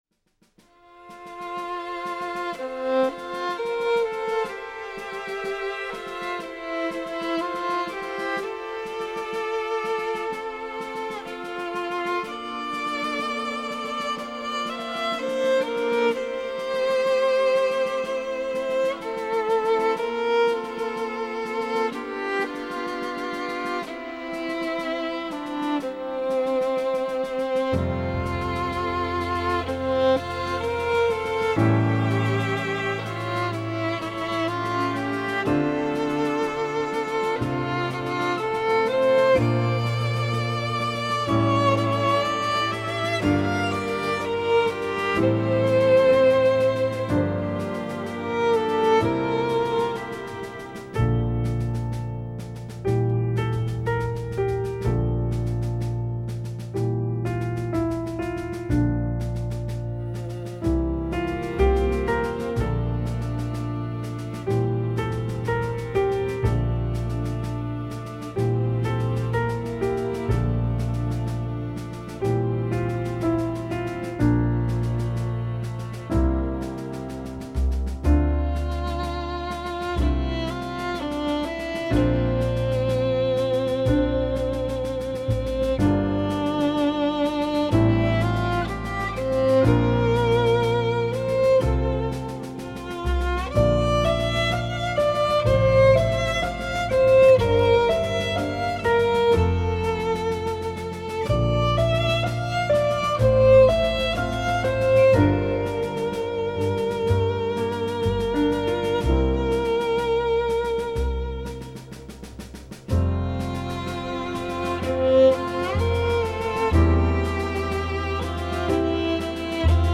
ジャズ×クラシック・クロスオーバー の感動作
Drums, Percussions, Melodion, Andes25
Violin and Strings
Piano
Steel Pans
レコーディング・スタジオ：CRESCENTE STUDIO、STRIP STUDIO